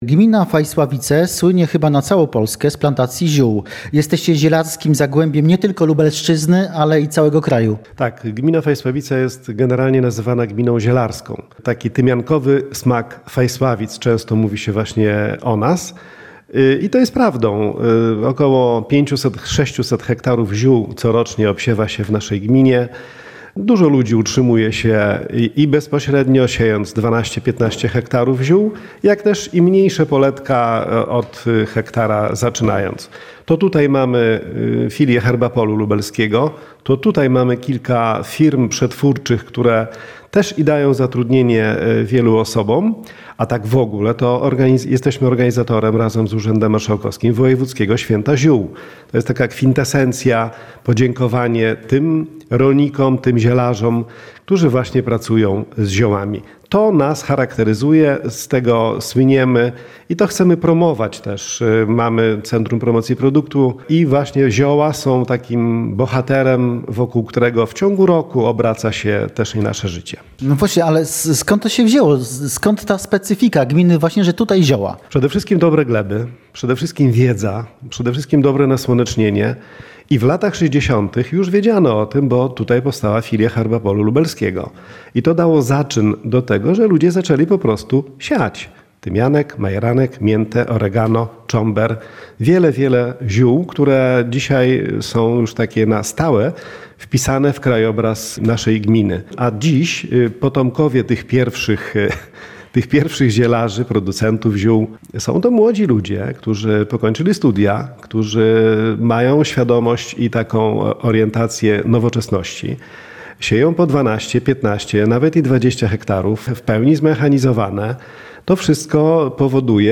O planach na rozwój "tymiankowego" zagłębia Lubelszczyzny oraz otwartych sercach mieszkańców z wójtem gminy Fajsławice, pow. krasnostawski, Januszem Pędziszem rozmawia